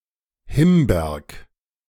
Himberg bei Wien (German pronunciation: [ˈhɪmˌbeɐk]